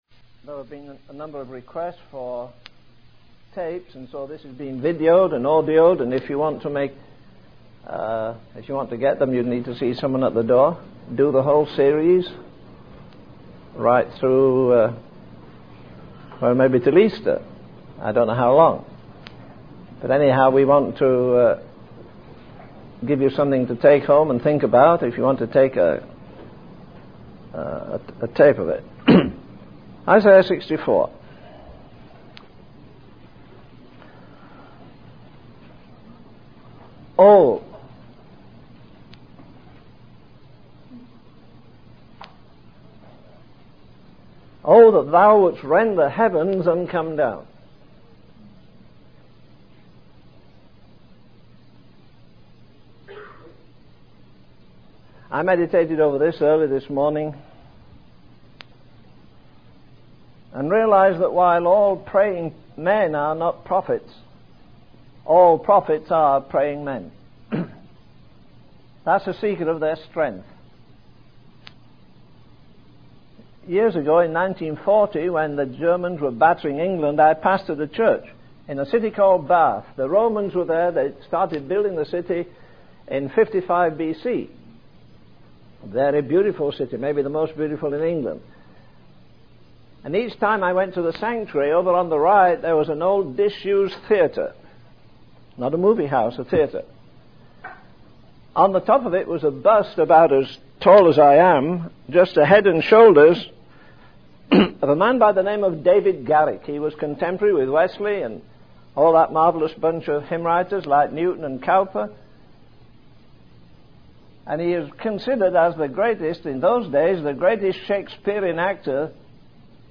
In this sermon, the speaker emphasizes the importance of prayer and the role it plays in the lives of prophets. He shares a personal experience from 1940 when he was in Bath, England during the German bombings. The speaker criticizes the lack of impact that the church has had on society despite the abundance of resources available.